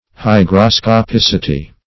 Search Result for " hygroscopicity" : The Collaborative International Dictionary of English v.0.48: Hygroscopicity \Hy`gro*sco*pic"i*ty\, n. (Bot.) The property possessed by vegetable tissues of absorbing or discharging moisture according to circumstances.